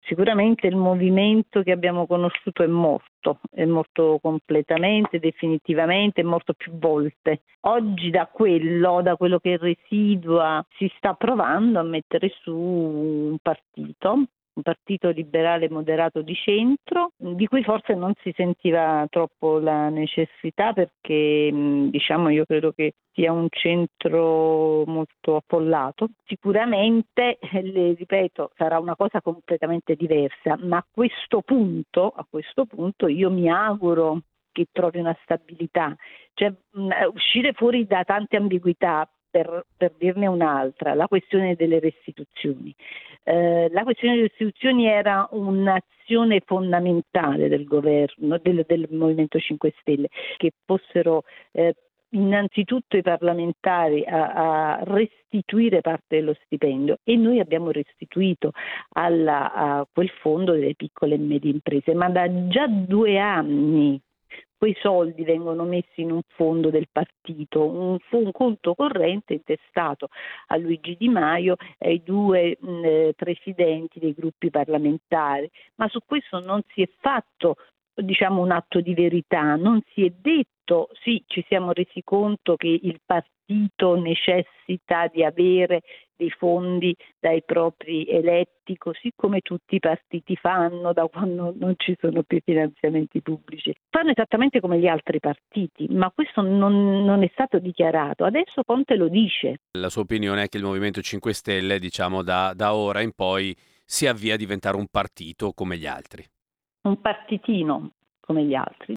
Abbiamo intervistato una ex del Movimento: Paola Nugnes, senatrice, ex 5 stelle.